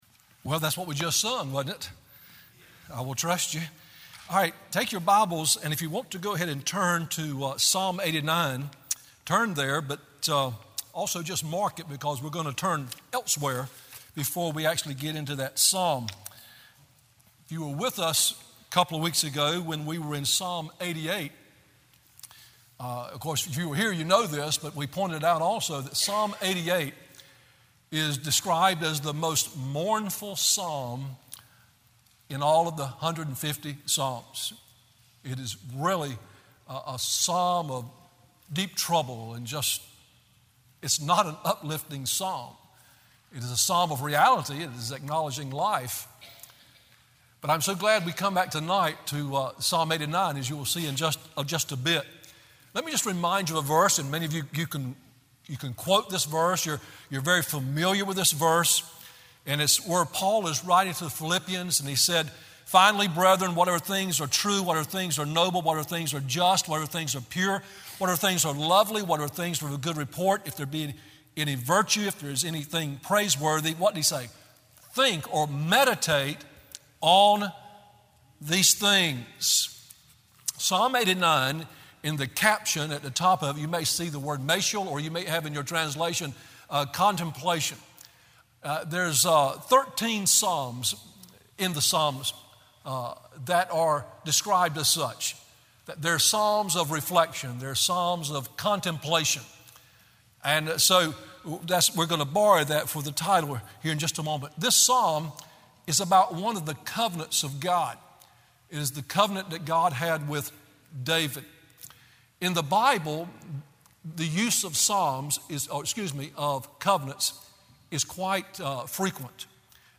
Sermon Audios/Videos - Tar Landing Baptist Church